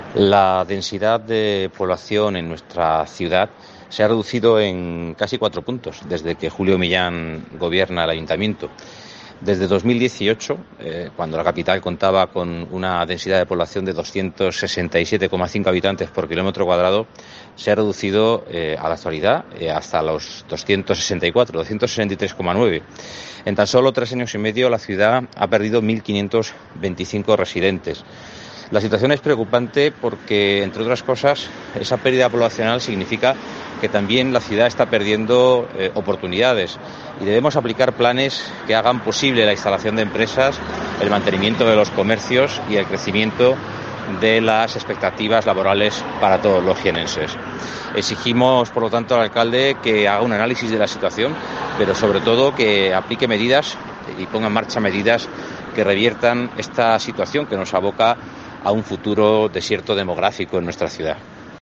Manuel Bonilla es el portavoz del PP de Jaén